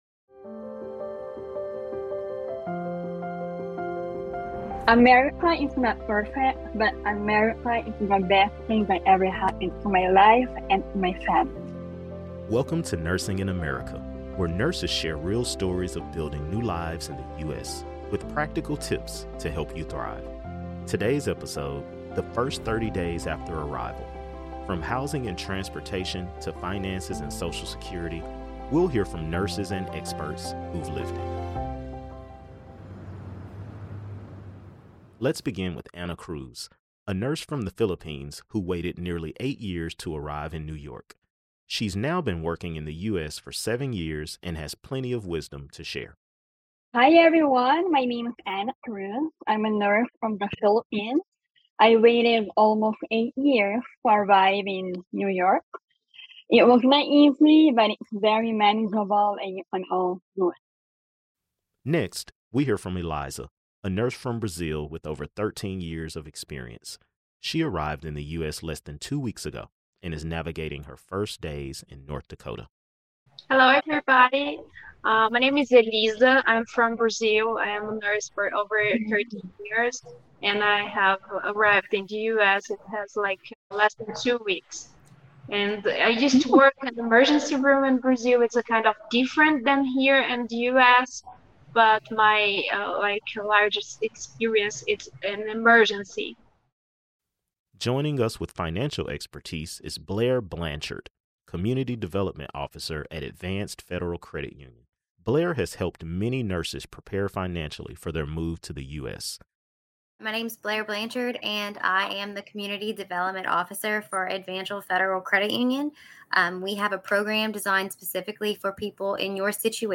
Nurses and experts share their personal stories and practical advice on navigating housing, transportation, finances, and social security.